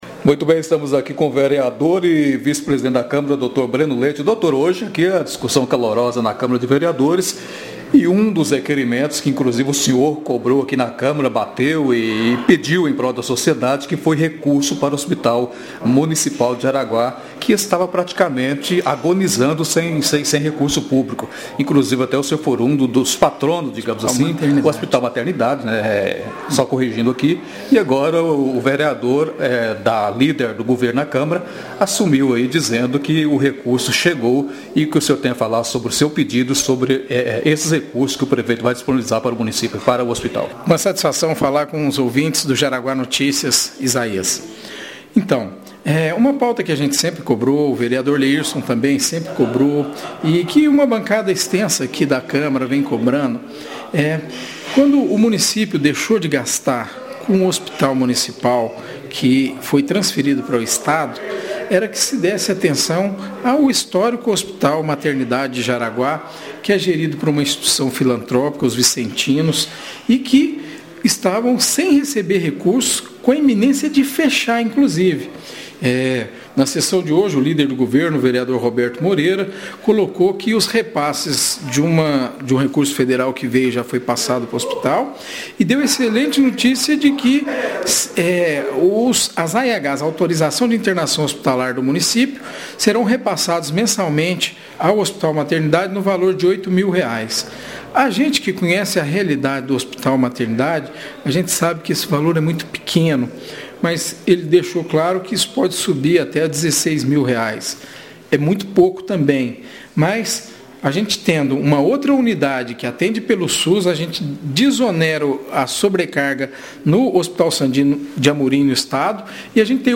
Segundo Breno Leite, essa foi mais uma conquista da população e um avanço na administração da saúde pública municipal, onde agradeceu ao prefeito Zilomar Oliveira e aos demais vereadores pelo recurso pactuado com o Hospital Maternidade. Ouça a entrevista: